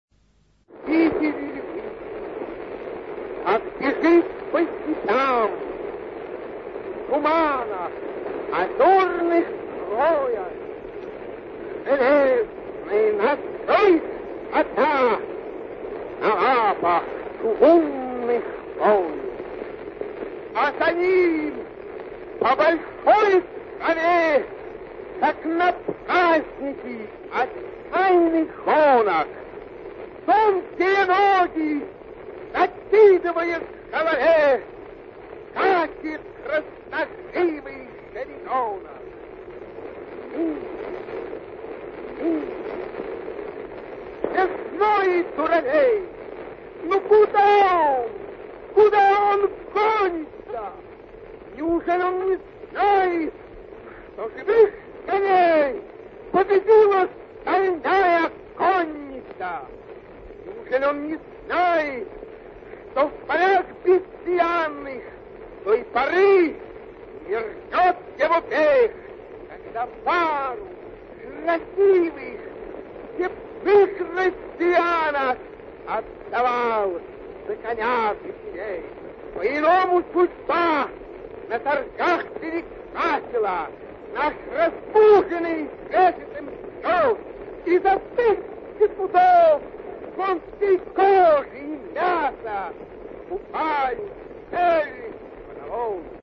Свои стихи читает автор - Сергей Александрович Есенин
Спасибо за авторскую декламацию С. А. Есенина:)